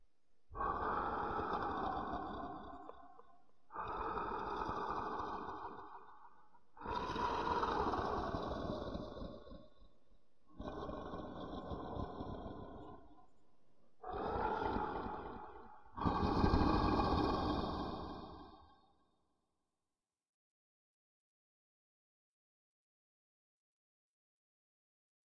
怪物的咆哮" 咆哮3
描述：一个怪物的咆哮声以吼叫声结束。
标签： 咆哮 怪物 轰鸣
声道立体声